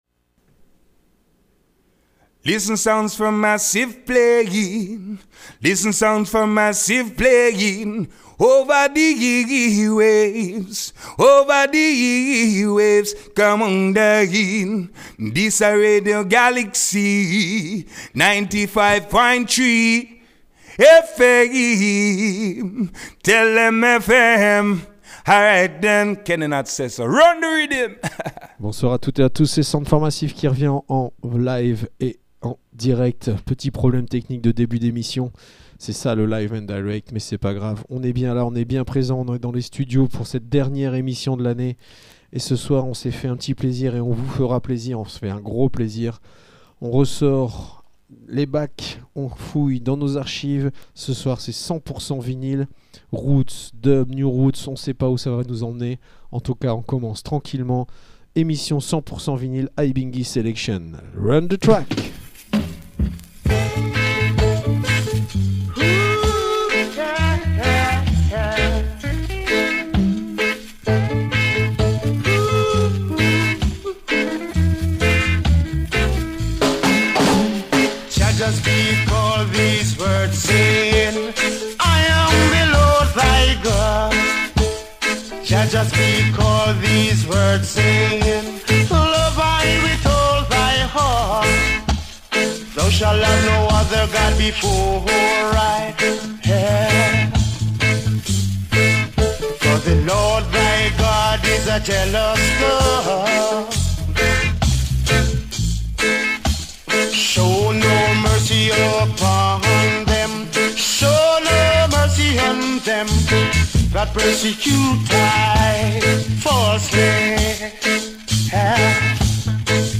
reggaephonique
enregistré lundi 29 décembre dans les studios
Radio Show